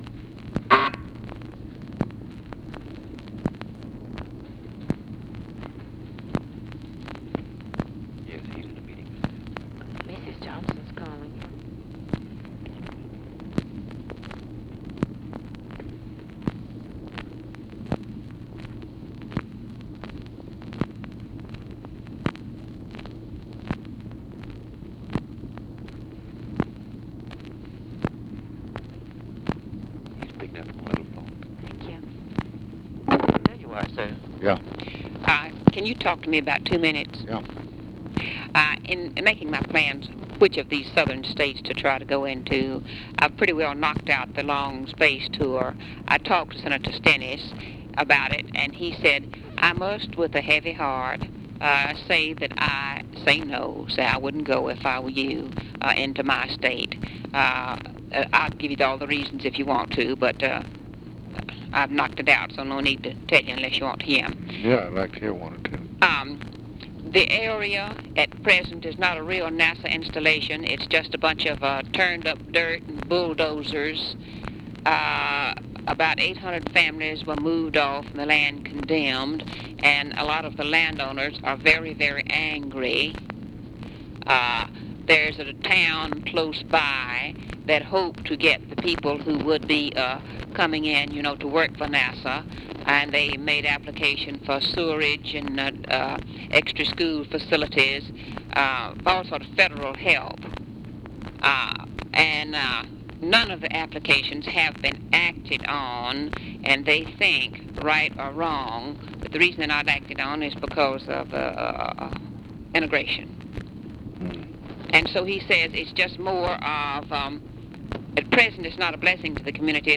Conversation with LADY BIRD JOHNSON and OFFICE CONVERSATION, March 10, 1964
Secret White House Tapes